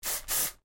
Звуки дезодоранта
Шипящий звук спрея дезодоранта